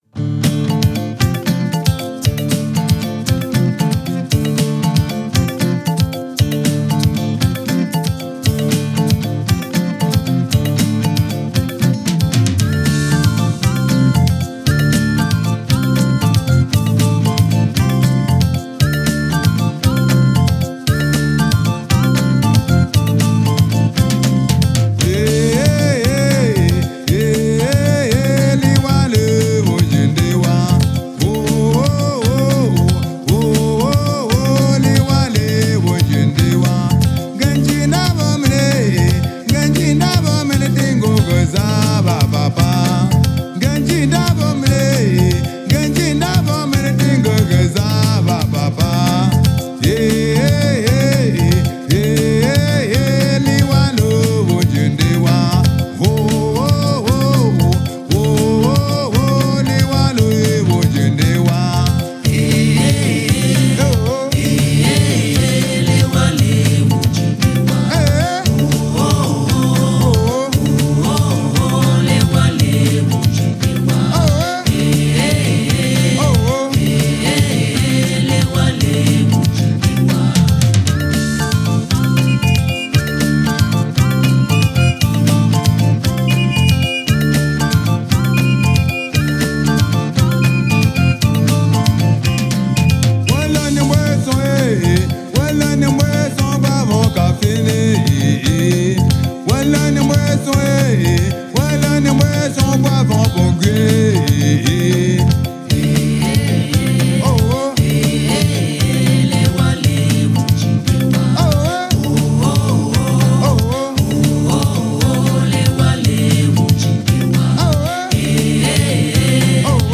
Genre: Africa, World